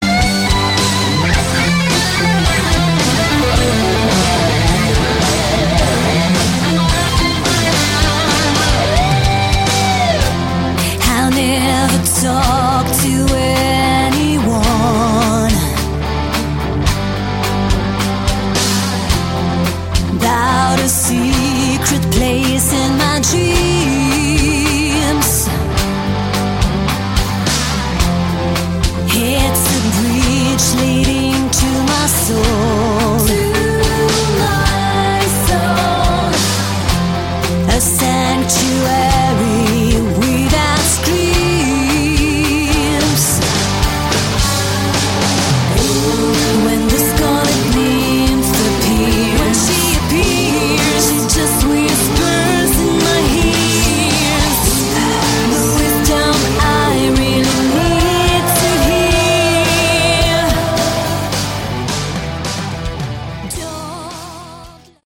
Category: Melodic Rock
lead and backing vocals